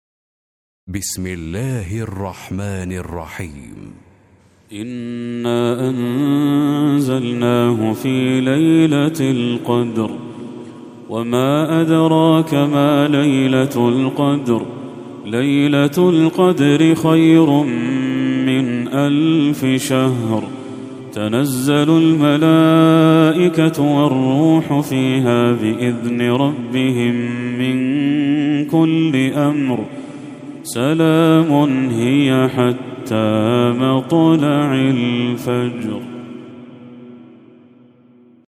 سورة القدر Surat Al-Qadr > المصحف المرتل